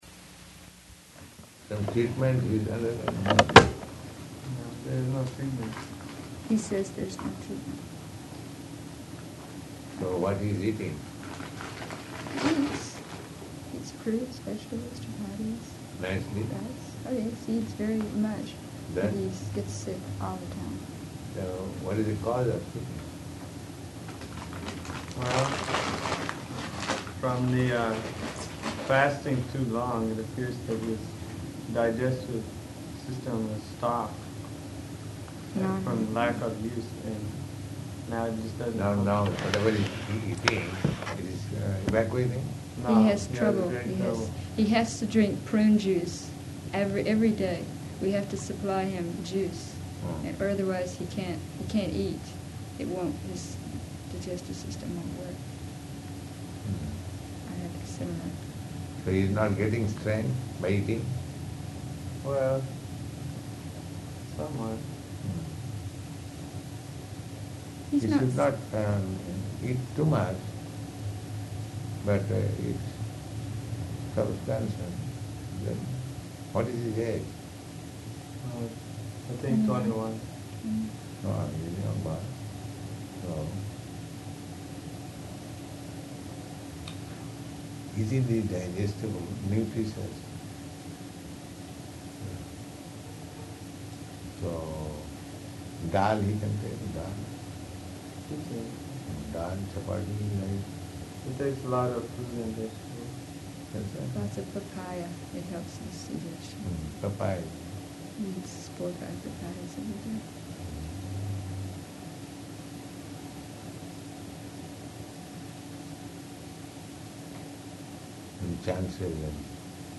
Room Conversation
Location: Los Angeles